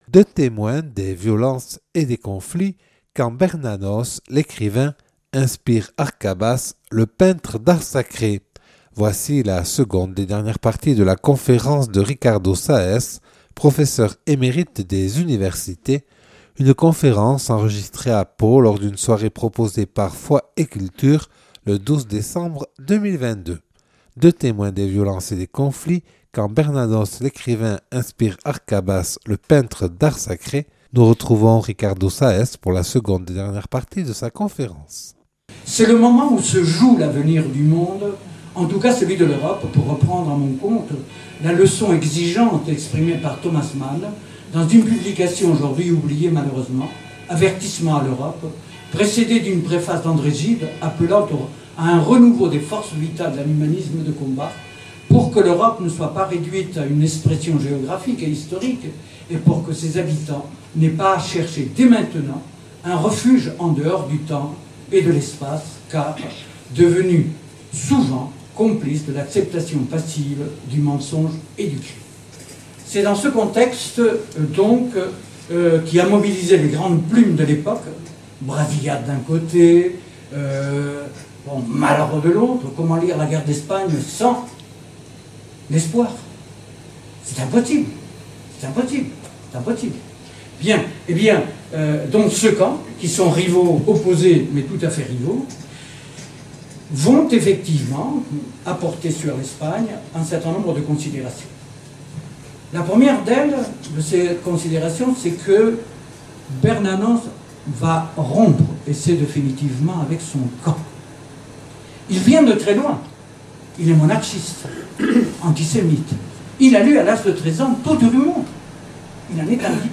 Une conférence
professeur émérite des universités (Enregistré le 12 décembre 2022 à Pau lors d’une soirée proposée par « Foi et Culture »)